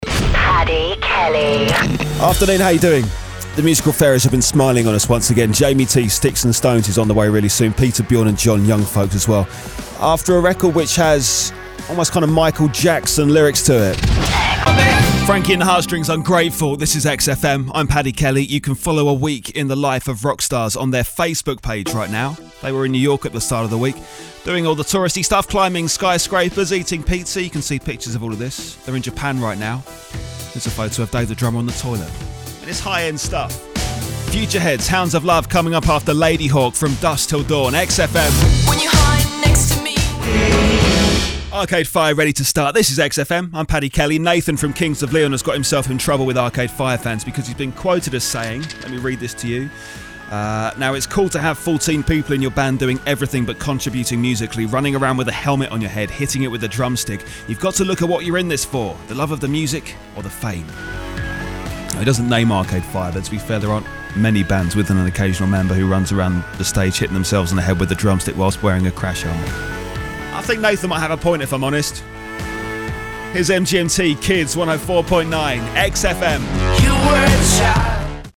From cool & credible to energetic sports commentator. A hip, young sell for your product.